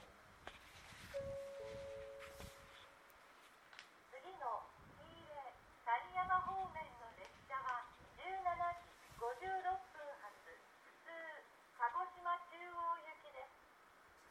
この駅では接近放送が設置されています。
接近放送普通　鹿児島中央行き予告放送です。